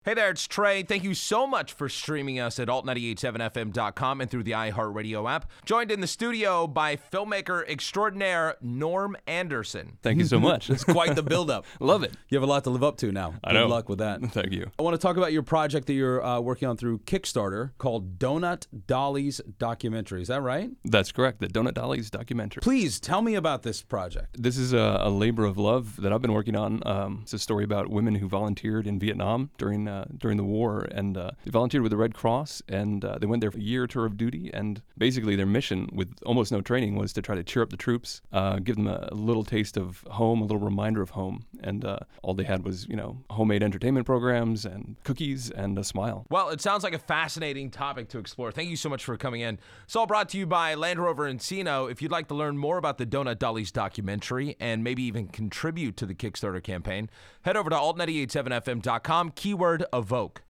Check out the spot that’s been airing on Alt 98.7 and the iheartmedia app below.